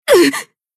BA_V_Marina_Battle_Damage_1.ogg